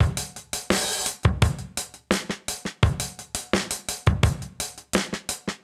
Index of /musicradar/sampled-funk-soul-samples/85bpm/Beats
SSF_DrumsProc2_85-04.wav